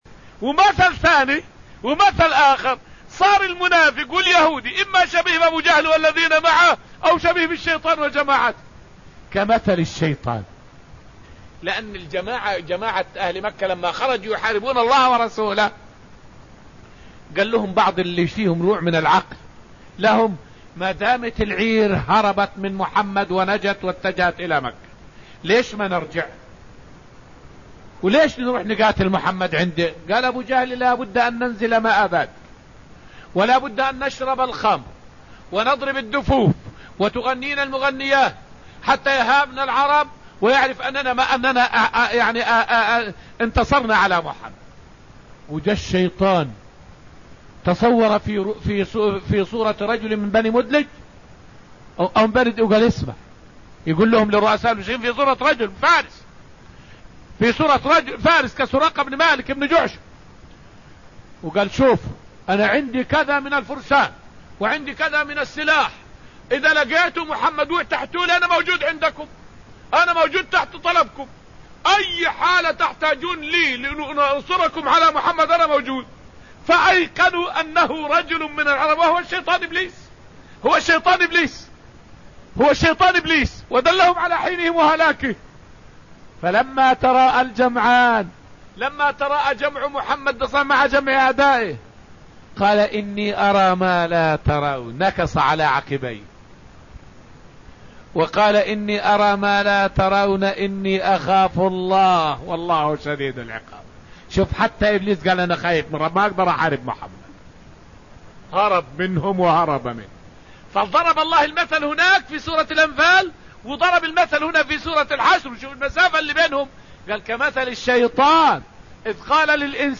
فائدة من الدرس التاسع من دروس تفسير سورة الحشر والتي ألقيت في المسجد النبوي الشريف حول قدرة الشيطان على التمثل بصورة بشر.